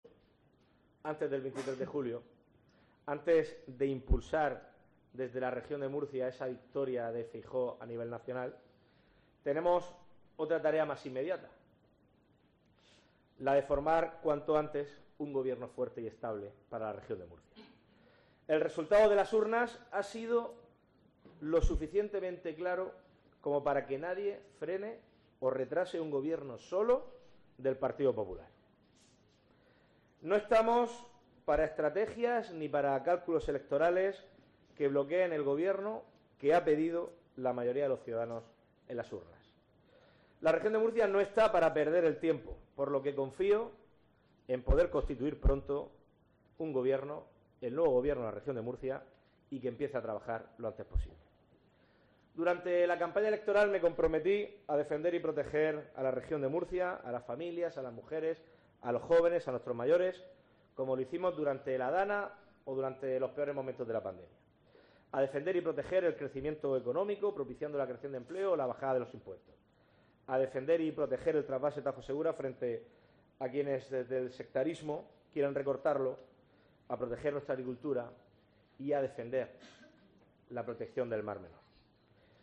El presidente del PP regional y presidente de la Comunidad en funciones, Fernando López Miras, ha señalado este miércoles, durante su intervención en la Junta Directiva Regional de su partido, que tienen la tarea de formar "cuanto antes" un gobierno "fuerte y estable" para la Región de Murcia, y ha advertido que "el resultado de las urnas ha sido lo suficientemente claro como para que nadie frene o retrase un gobierno solo del Partido Popular".